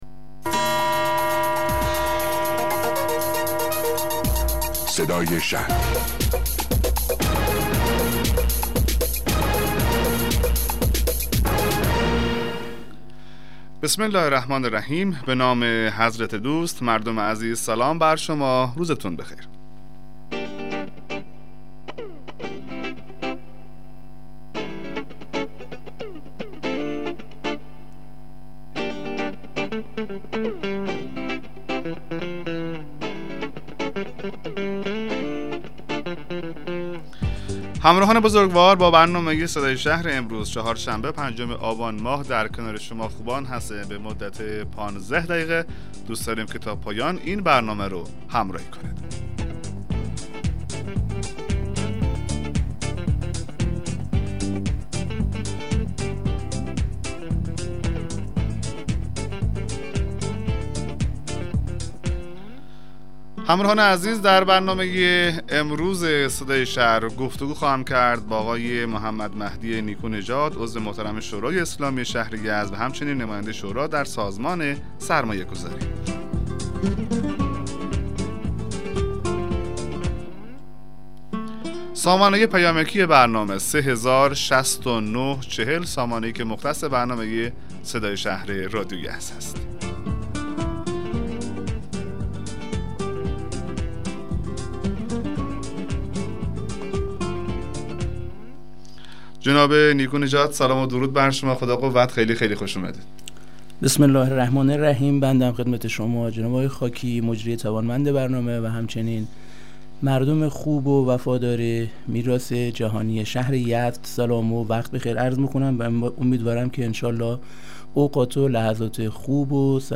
مصاحبه رادیویی برنامه صدای شهر با حضور محمدمهدی نیکونژاد عضو کمیسیون بودجه شورای اسلامی شهر یزد